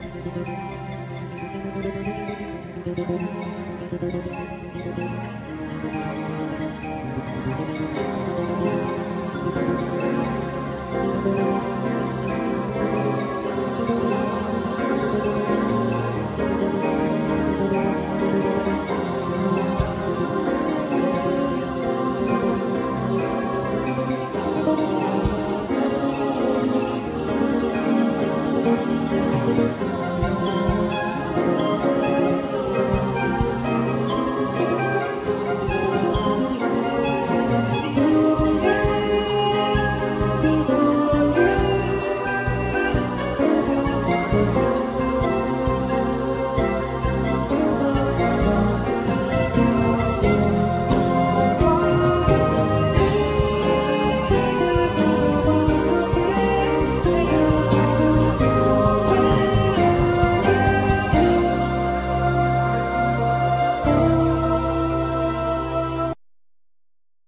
Bass,Ac.guitar,Percussions,Vocal
Synthesizer,Percussions,Vocal
Flute,Percussions,Sopranosaxophne
Drums
Piano